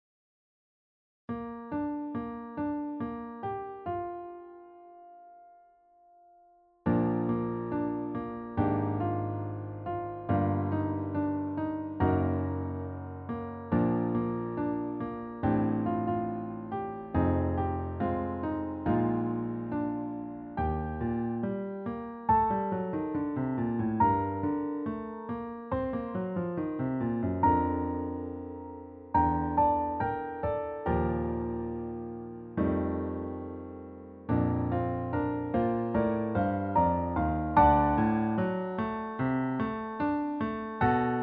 You Cannot Cancel Christmas – Piano TracksDownload